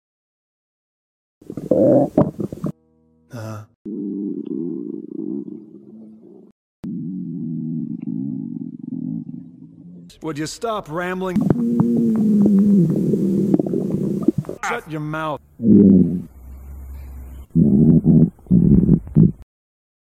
Benimaru's tummy rumbling (audio)
Beni's getting annoyed at his noisy tummy rumbling, he's pretty hungry as you can hear. (voice clips taken from the eng dub of the anime)